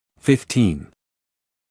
[ˌfɪf’tiːn][ˌфиф’тиːн]пятнадцать